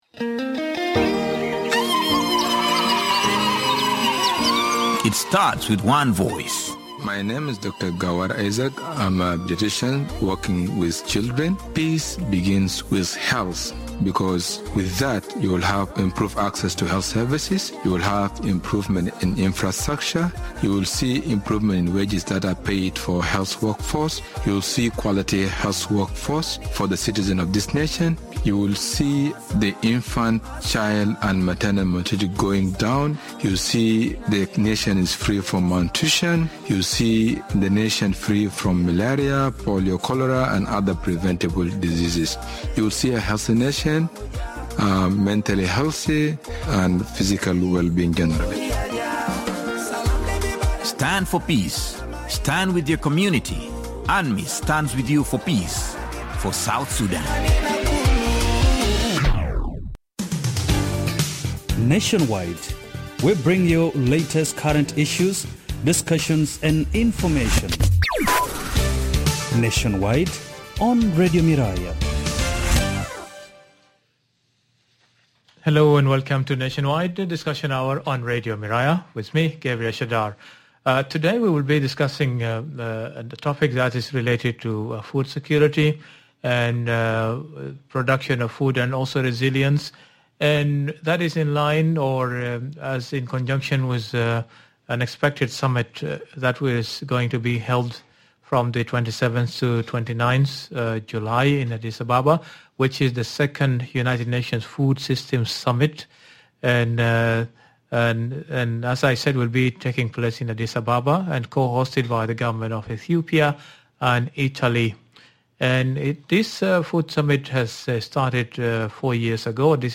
Guests: - Hon. Dr. John Ogoto Kaniso Lefuk, Undersecretary for Food Security, Ministry of Agriculture and Food Security